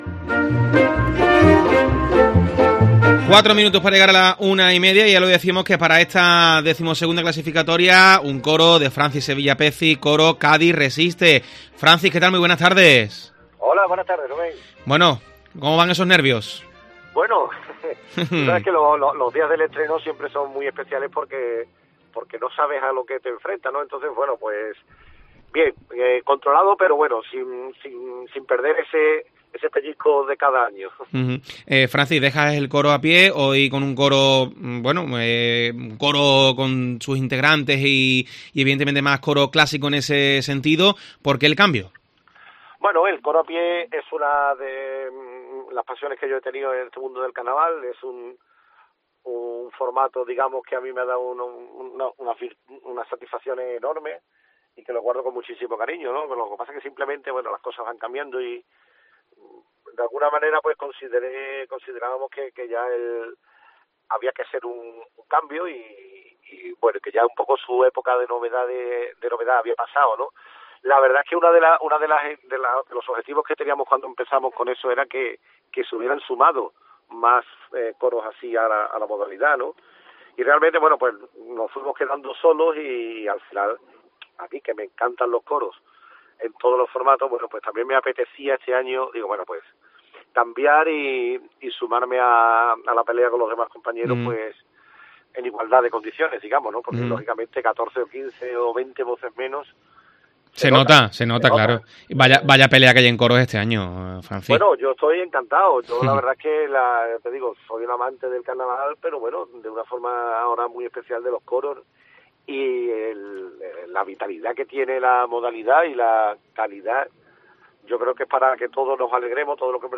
Hablamos con el autor antes de su estreno